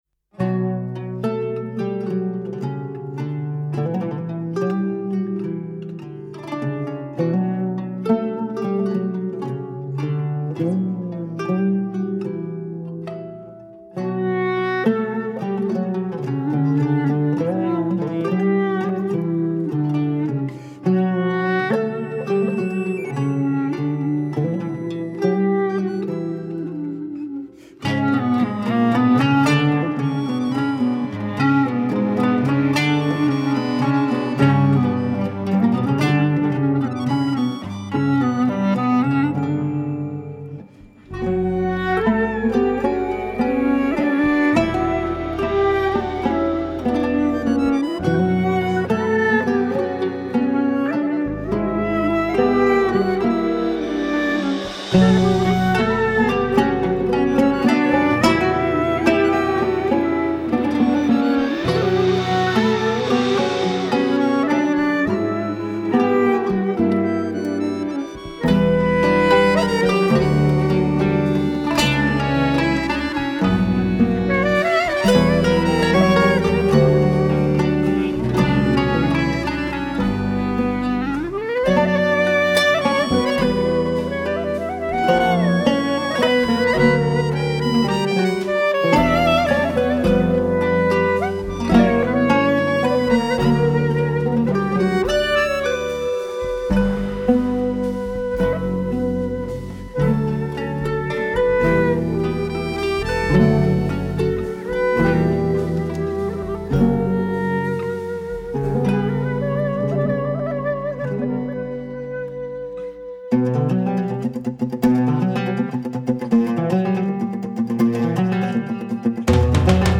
Percussions
Sax
Violin
Oud
Kanun
Accordeon
Clarinette
Drums
Bass